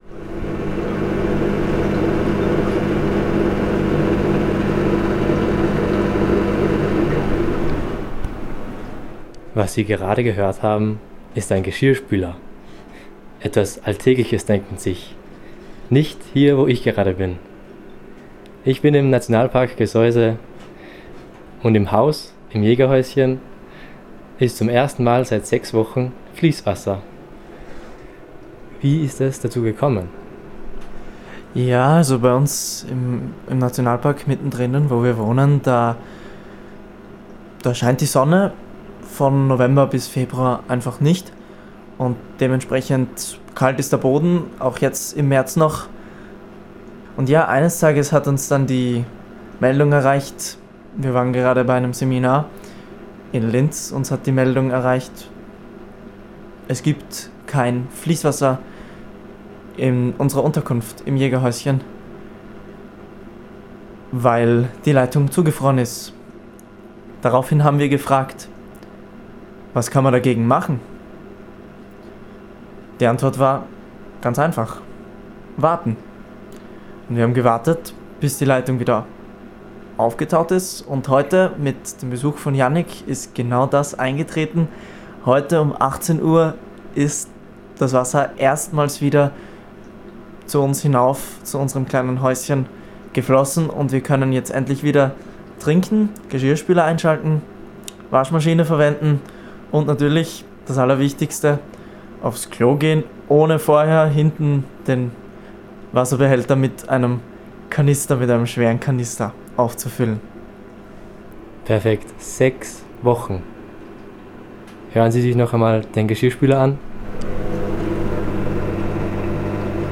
Radio Libre Regional – Reportage aus dem Nationalpark Gesäuse
Beginnend mit der Ankunft in der Nacht, wird ein Arbeitstag im Nationalpark Schritt für Schritt begleitet.